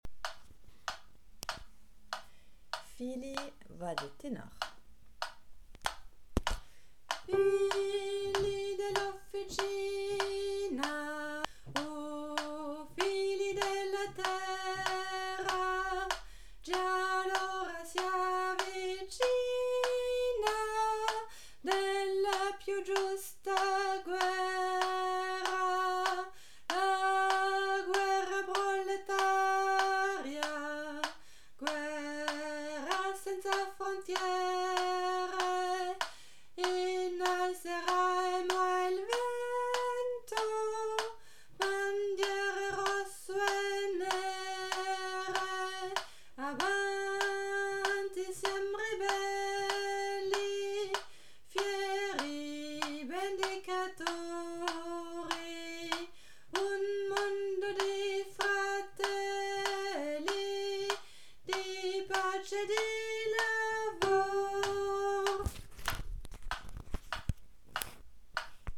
Figli tenor
figli-tenor.mp3